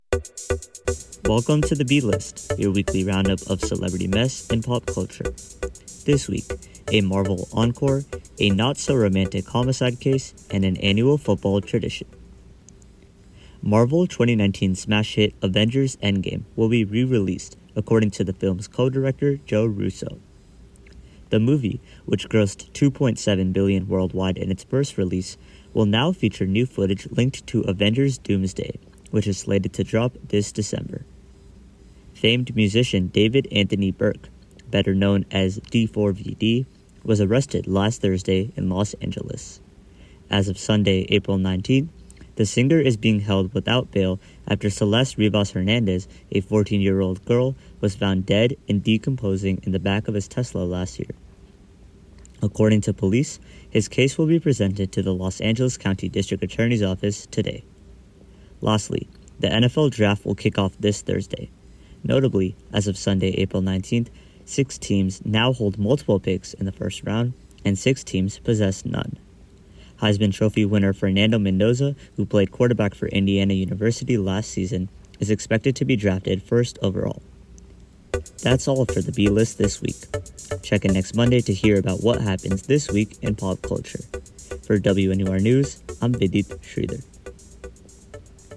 Theme music is from MixKit “What What?! Wowow!” by Michael Ramir C.